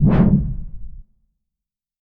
ENEMY_AGNI_ATTACK_2_SWING.ogg